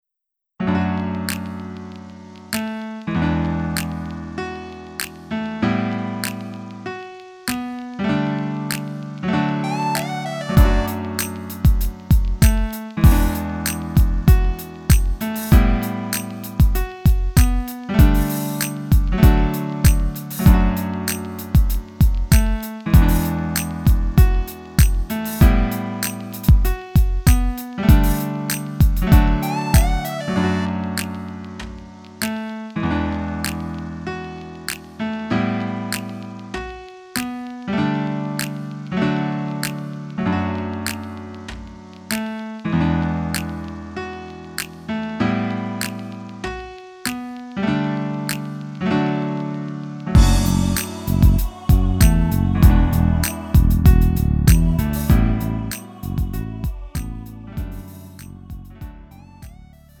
음정 -1키
장르 가요 구분 Lite MR
Lite MR은 저렴한 가격에 간단한 연습이나 취미용으로 활용할 수 있는 가벼운 반주입니다.